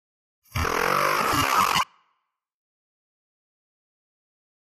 Alien Vocal Sound 2 Sci-Fi